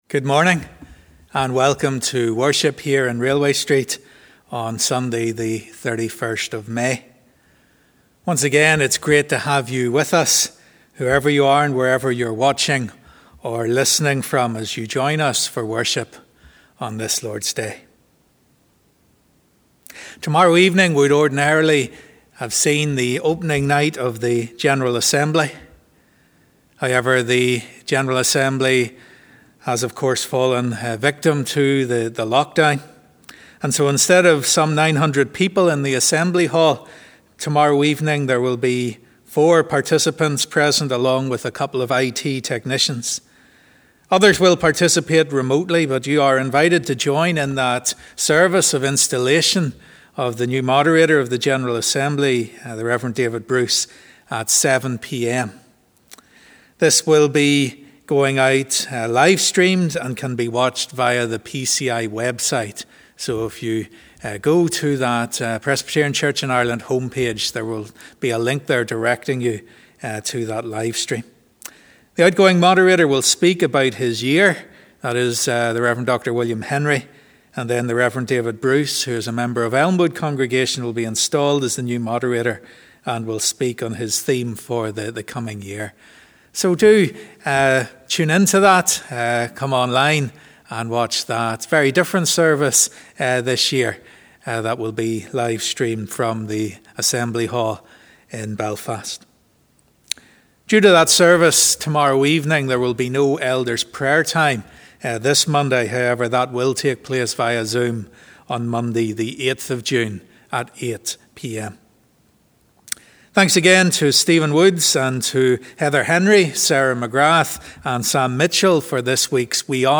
As we conclude let’s use the song 'Lord I Come To You'.
Morning Service